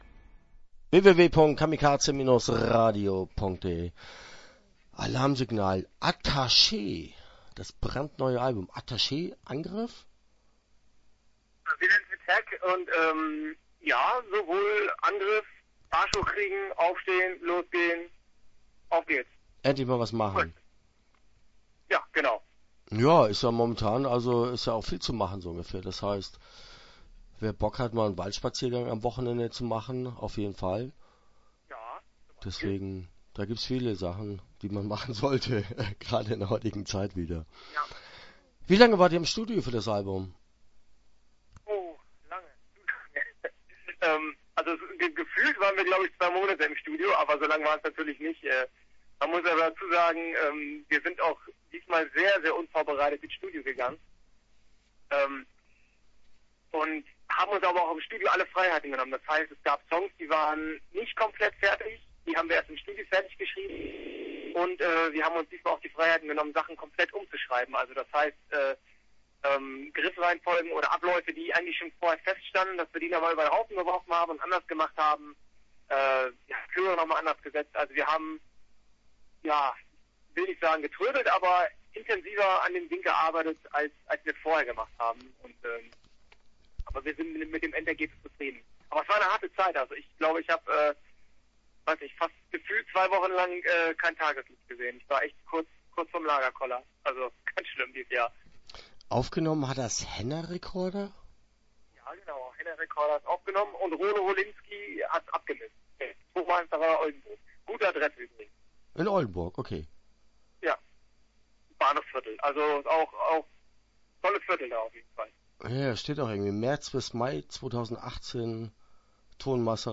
Alarmsignal - Interview Teil 2.